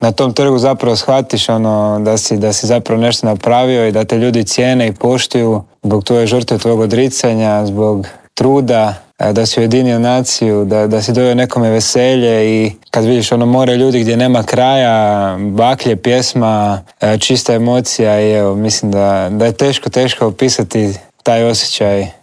Na početku se Glavaš u Intervjuu tjedna Media servisa osvrnuo na posljednji doček.